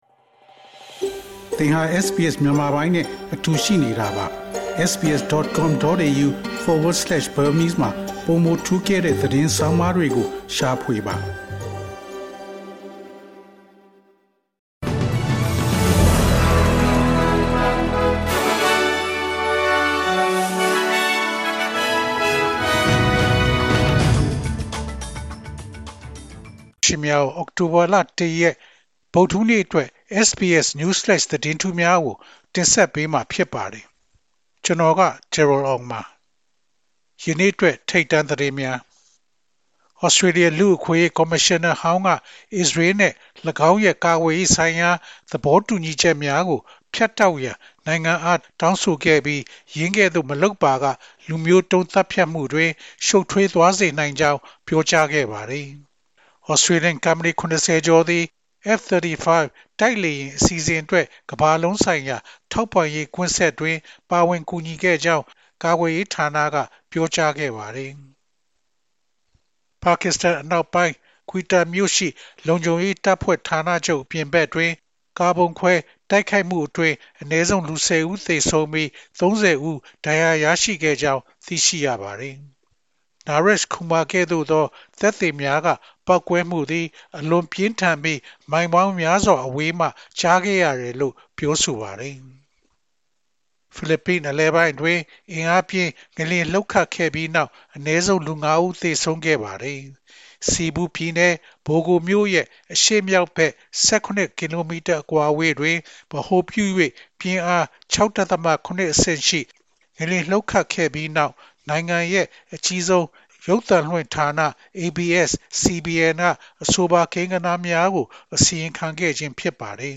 SBS မြန်မာ ၂၀၂၅ ခုနှစ် အောက်တိုဘာလ ၁ ရက် နေ့အတွက် News Flash သတင်းများ။